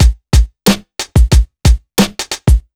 Index of /90_sSampleCDs/USB Soundscan vol.02 - Underground Hip Hop [AKAI] 1CD/Partition A/11-91BEATMIX